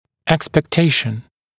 [ˌekspek’teɪʃn][ˌэкспэк’тэйшн]ожидание, ожидаемый результат, надежда